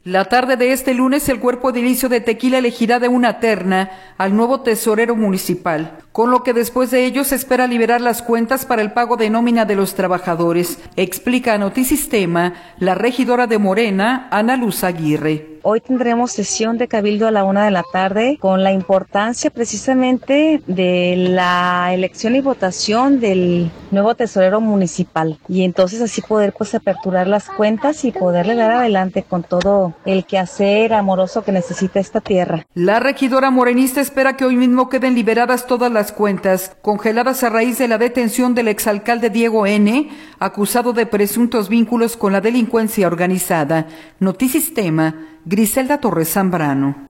La tarde de este lunes el cuerpo edilicio de Tequila elegirá de una terna al nuevo tesorero municipal, con lo que después de ello se espera liberar las cuentas para el pago de nómina de los trabajadores, explica a Notisistema la regidora de Morena, Ana Luz Aguirre.